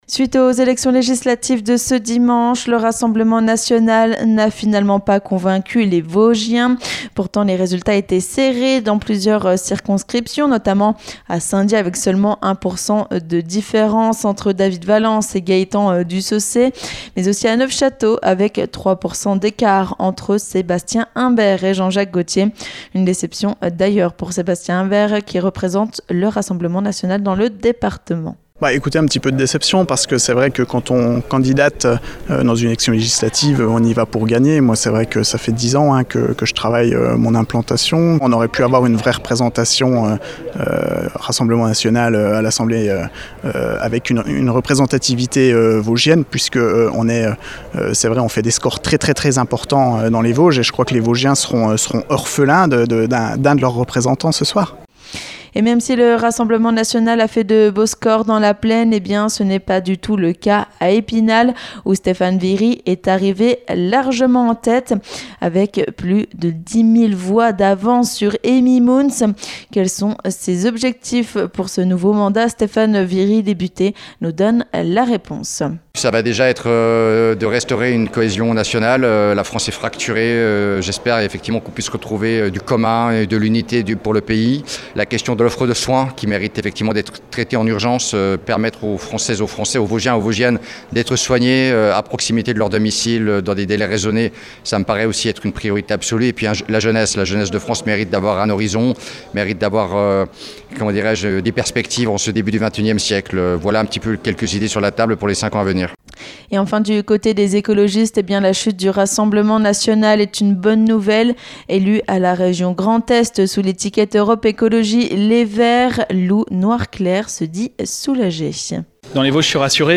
Après le deuxième tour des élections législatives dans les Vosges, le Rassemblement National n'a finalement pas convaincu le léctorat ! A l'occasion de la soirée électorales de la Préfecture des Vosges, nous sommes allés à la rencontre de différents élus.